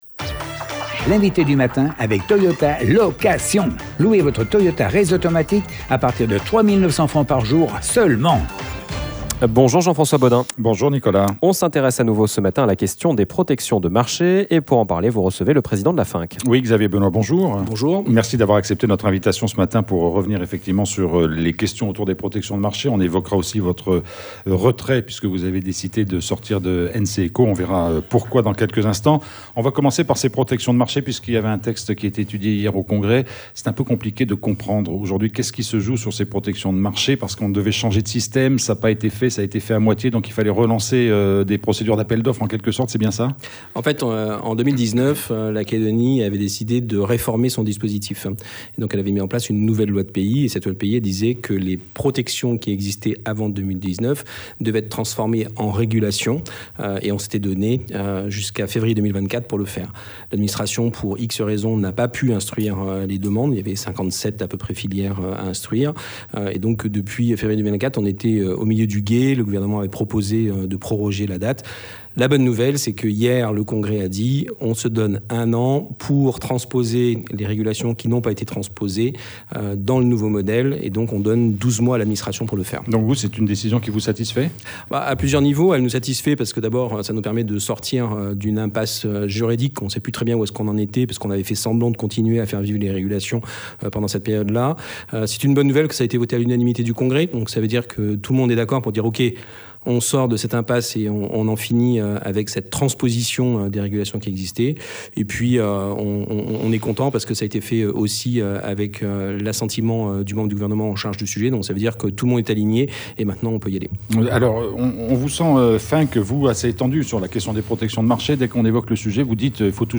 Il était notre invité à 7h30.